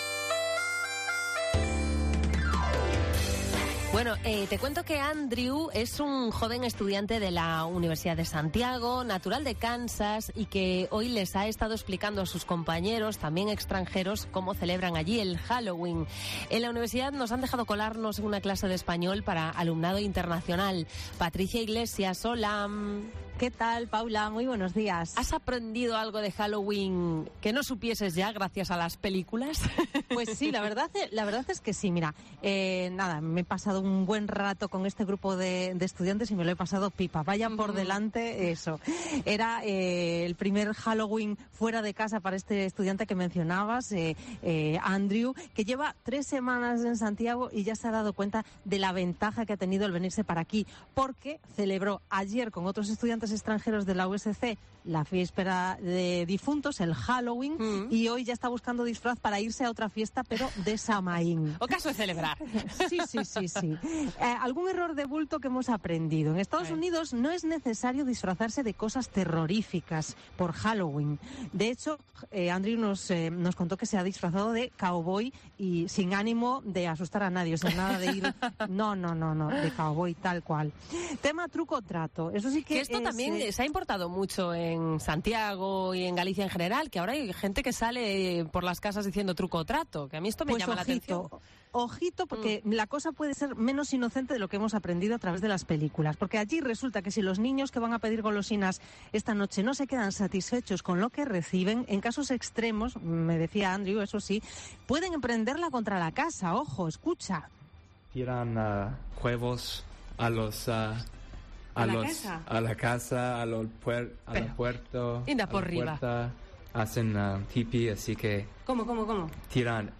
Nos colamos en una clase de español para extranjeros en Santiago
Clases de español para extranjeros en la USC